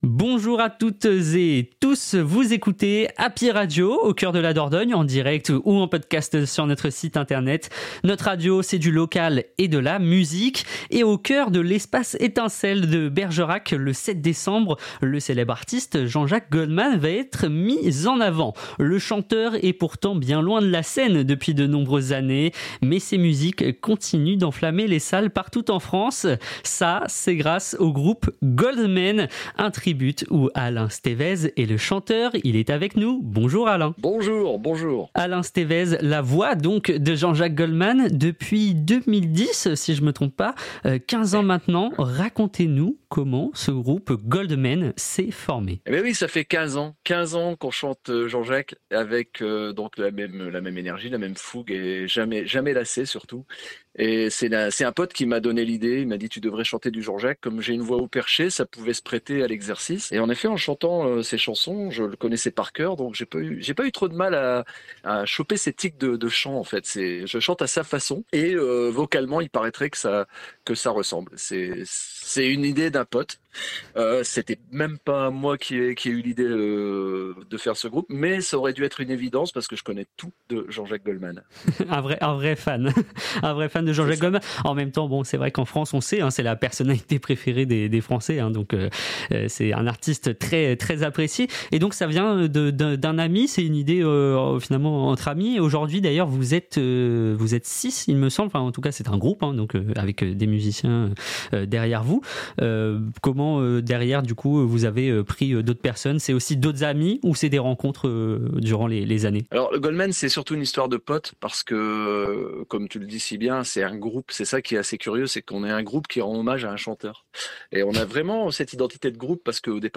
Les interviews Happy Radio